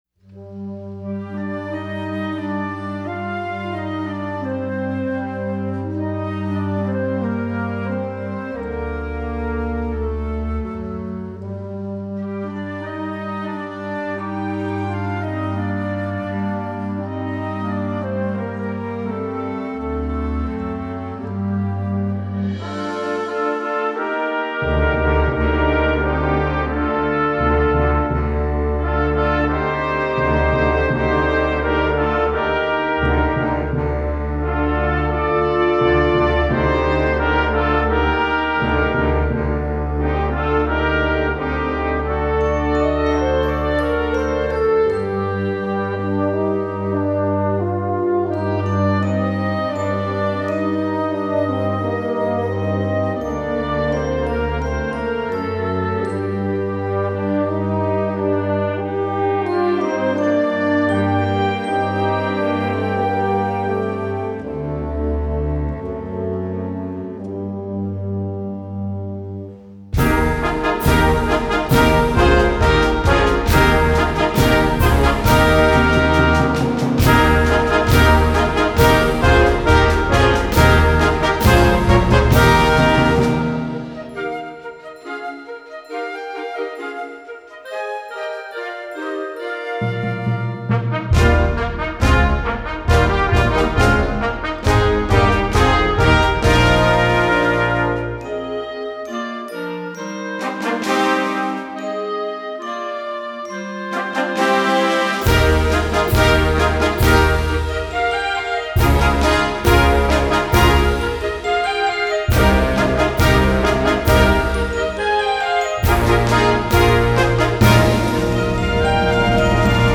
Répertoire pour Harmonie/fanfare - Concert Band